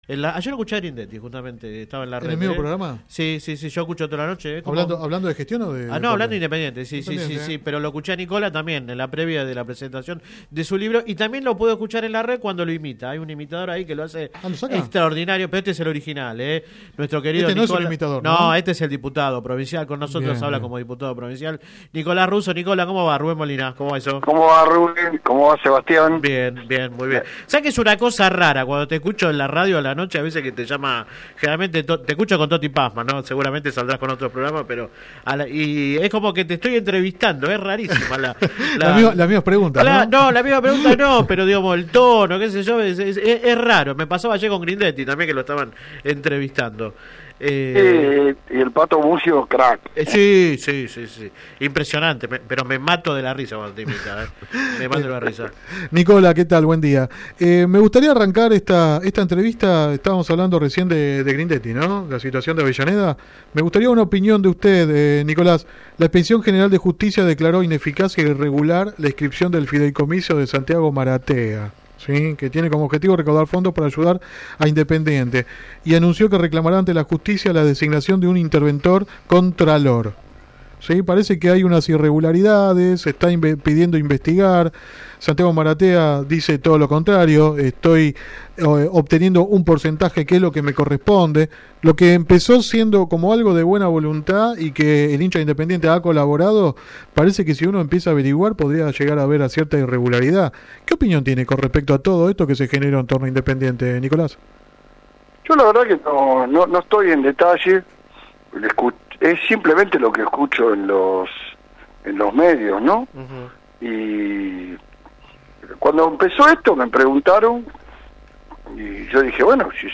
No descartó ir a unas PASO en el orden nacional y local, pero advirtió sobre la necesidad de “hacer las cosas bien” porque de lo contrario “podés quedar tercero”. El dirigente massista habló en el programa radial Sin Retorno (lunes a viernes de 10 a 13 por GPS El Camino FM 90 .7 y AM 1260).